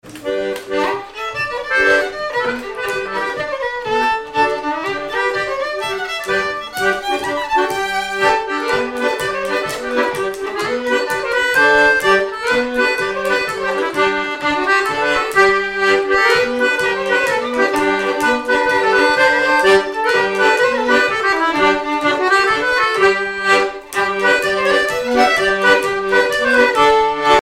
Miquelon-Langlade
violon
Pièce musicale inédite